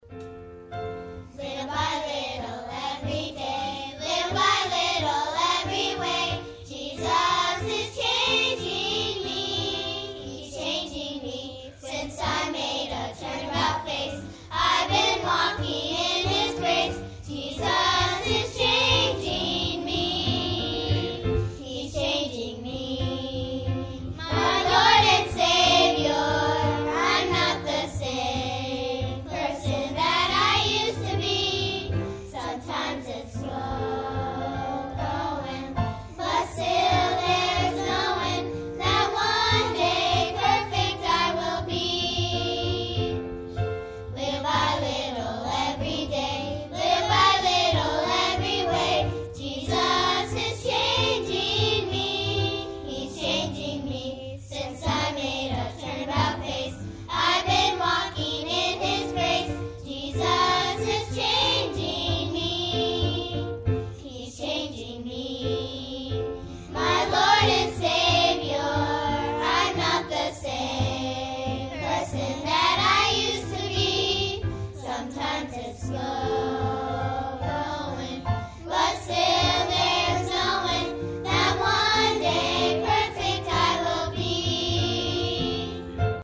Little by Little Kid's Choir 2010-03-28 Special Music 4478